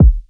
Boom-Bap Kick 89.wav